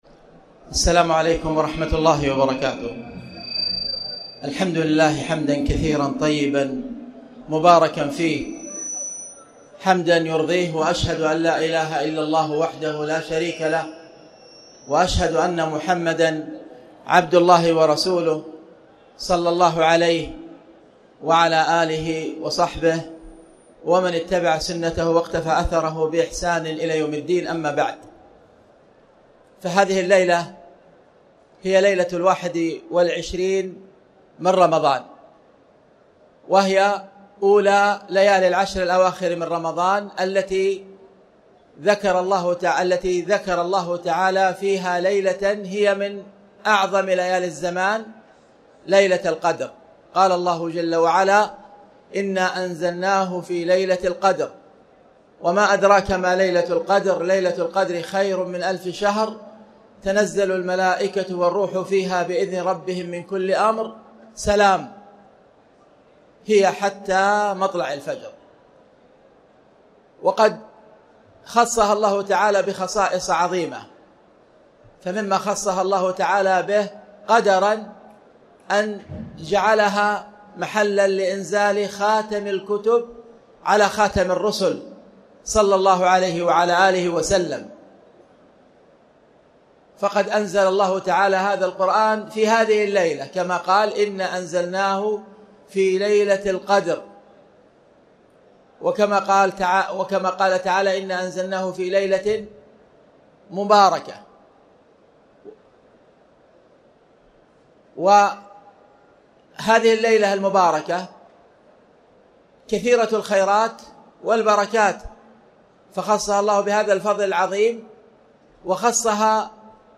تاريخ النشر ٢٠ رمضان ١٤٣٩ هـ المكان: المسجد الحرام الشيخ